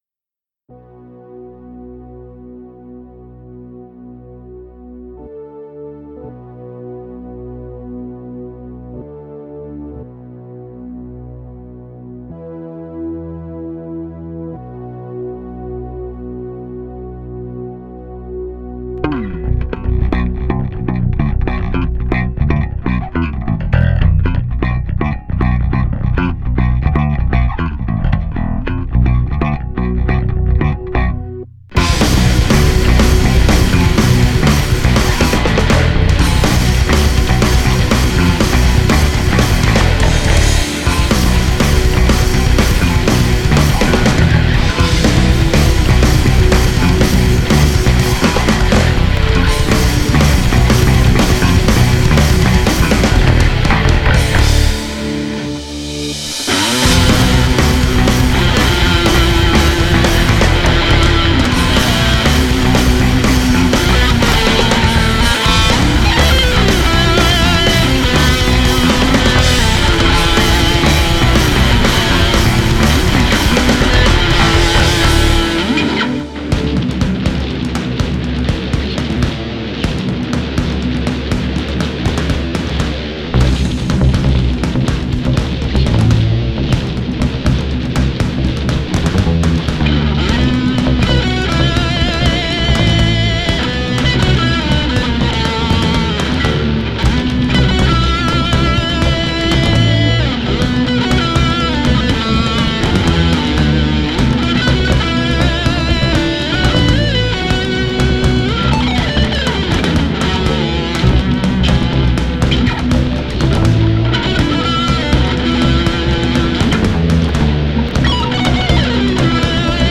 metal proggy instrumental